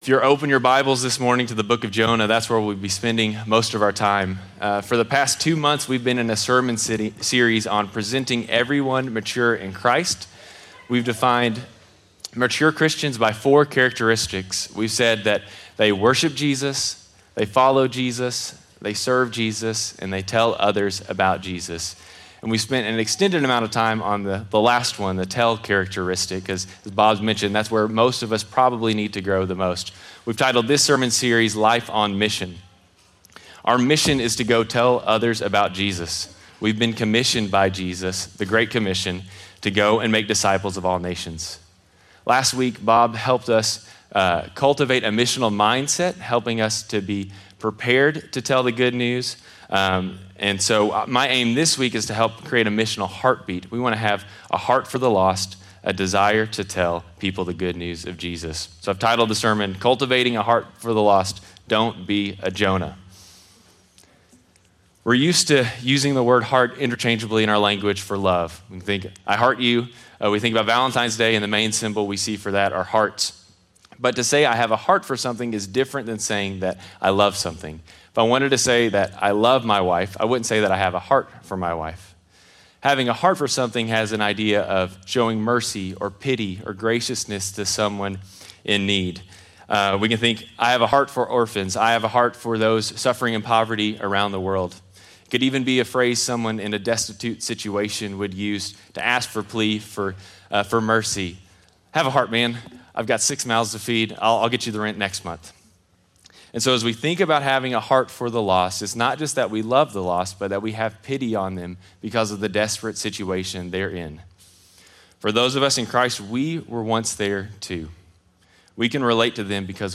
2024 Mature Disciples Jonah 4:1-11 The next sermon in our series about becoming a mature disciple continuing to unpack what it means to tell others of Christ by looking at the negative idea of Jonah from that same book.